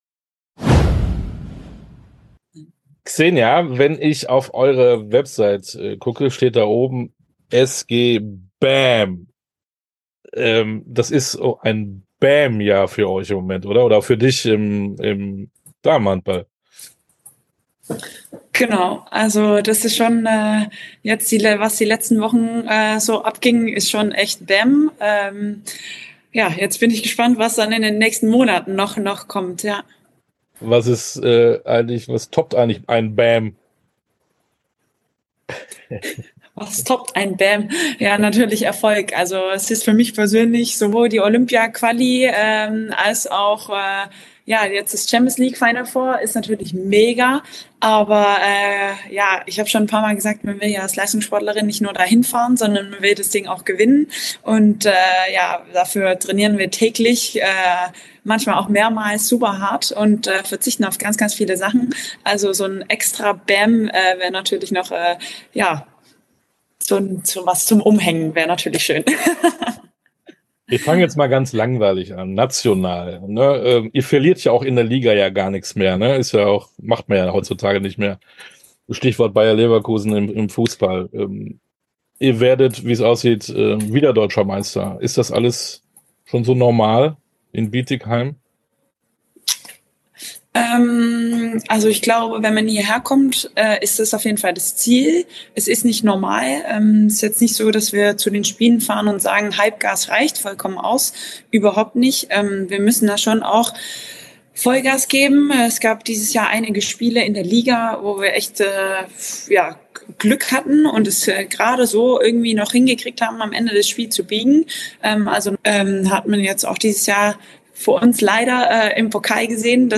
Sportstunde - Interview komplett Xenia Smits, Handball Nationalspielerin ~ Sportstunde - Interviews in voller Länge Podcast
Interview_komplett_Xenia_Smits-_Handball-Nationalspielerin_(1).mp3